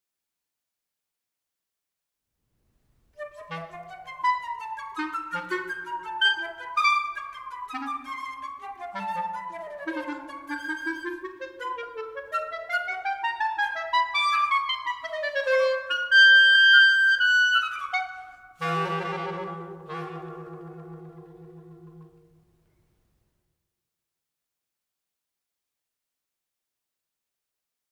5. Nagging relentlessly